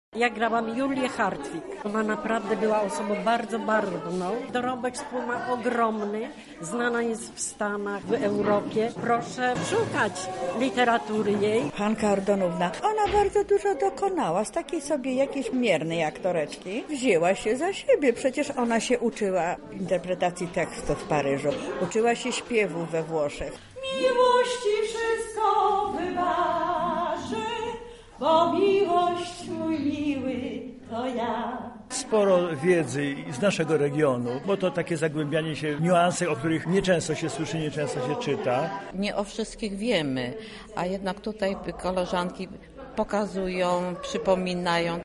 Na scenie wystąpiły artystki ze Stowarzyszenia Terraz Senioras.